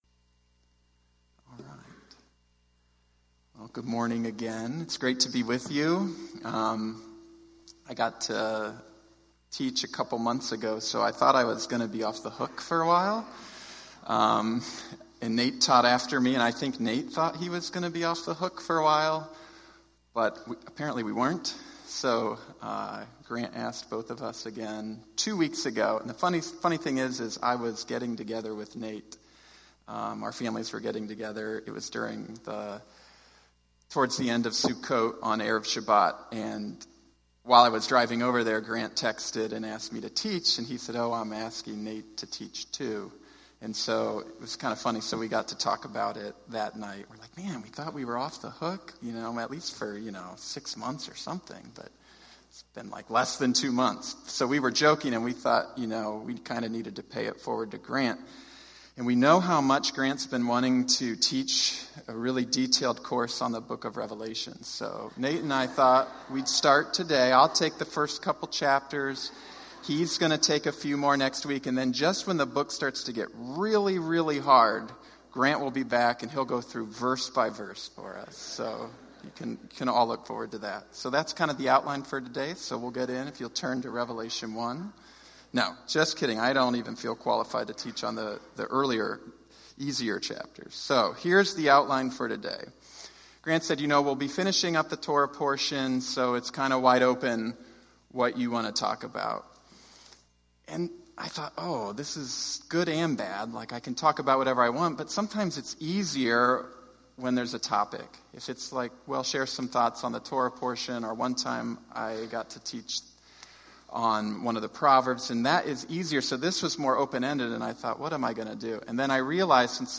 Special Teachings